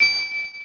pearl_picked.wav